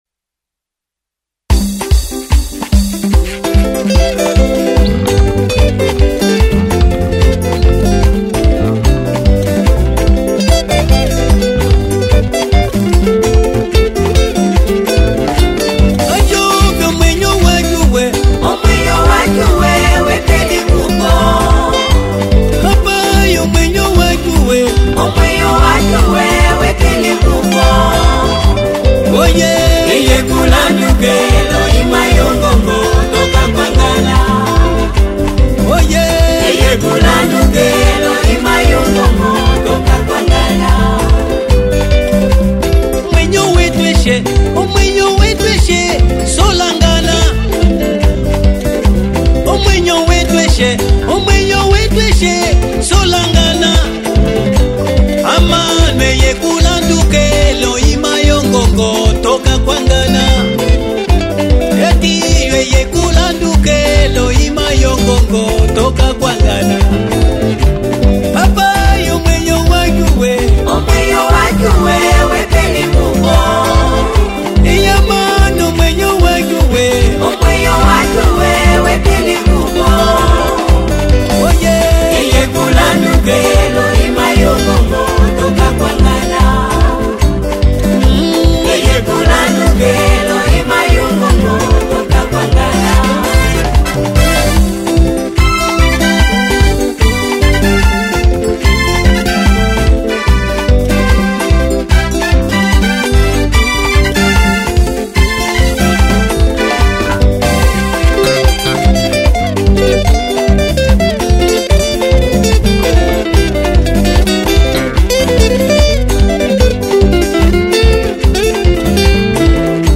Gospel 2022